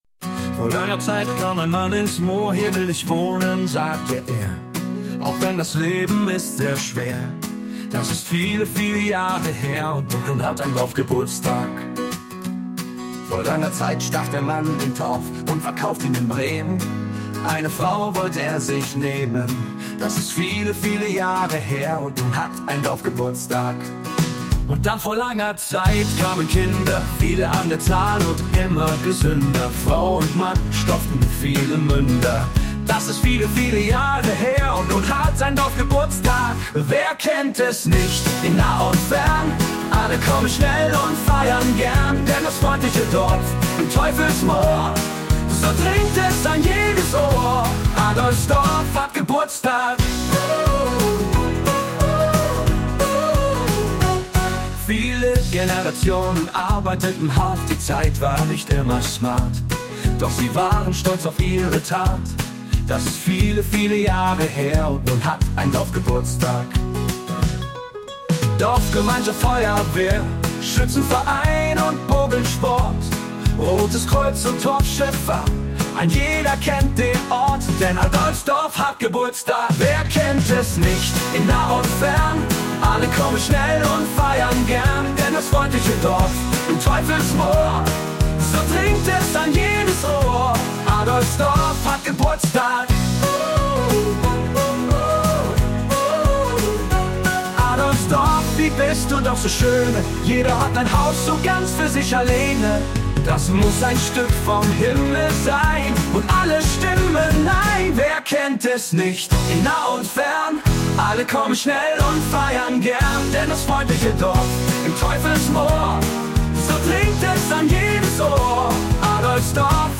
Die "Jazzin' Drifters":
Ich habe mir erlaubt ein wenig mit Künstlicher Intelligenz zu spielen und diese drei "Tracks" (als MP3) sind dabei herausgekommen.